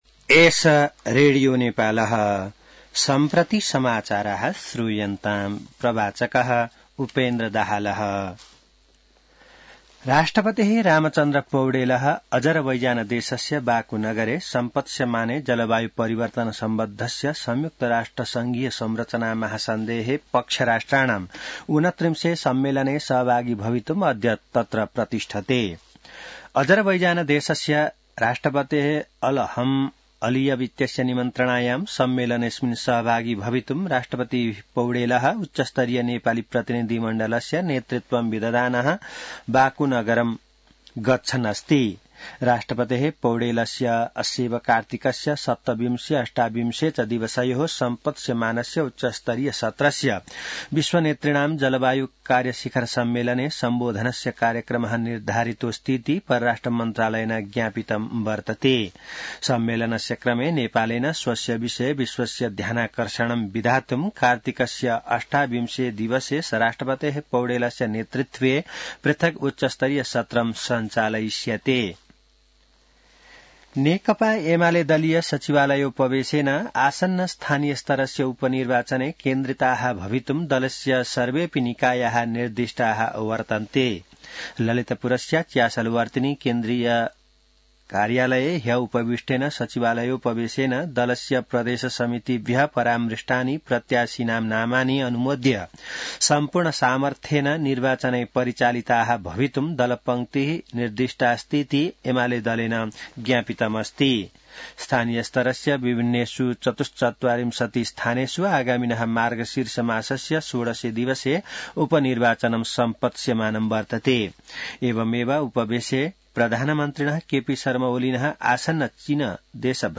संस्कृत समाचार : २६ कार्तिक , २०८१